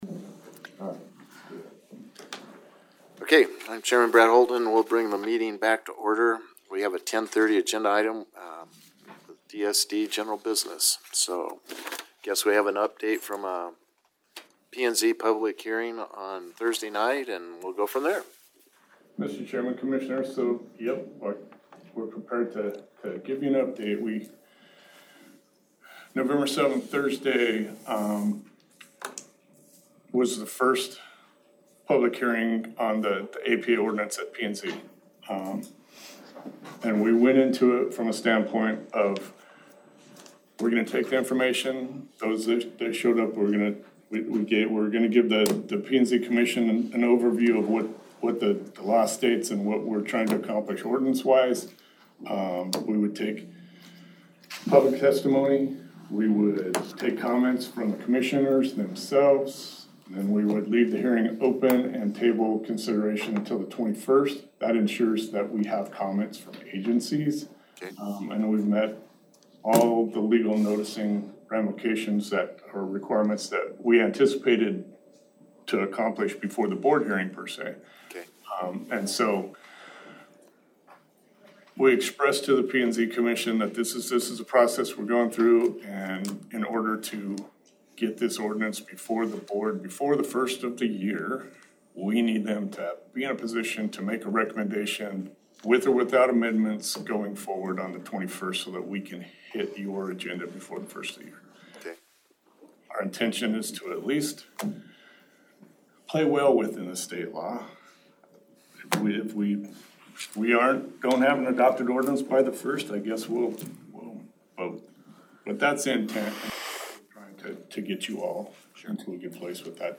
Agricultural Protection Act — Public Meeting